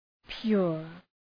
{pjʋr}